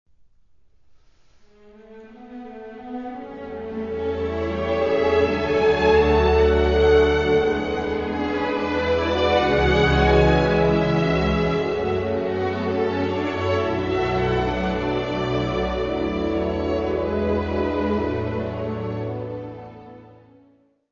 Área:  Música Clássica
Adagio.